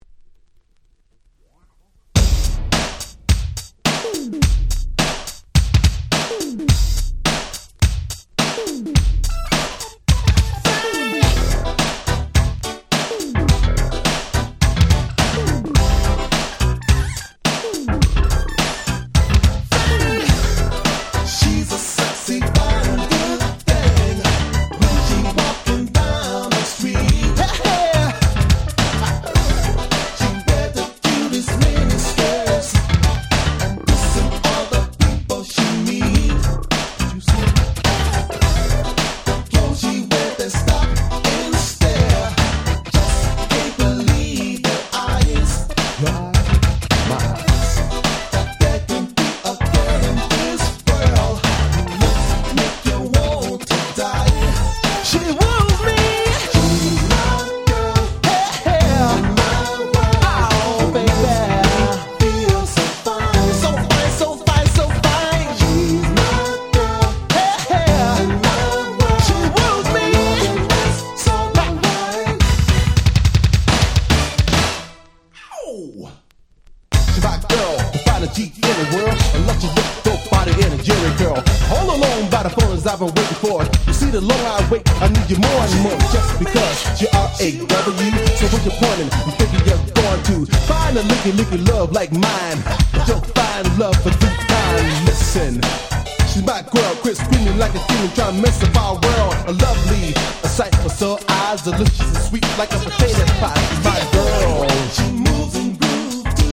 Funkyでハネっハネ！！
90's ハネ系 ニュージャックスウィング スロウジャム バラード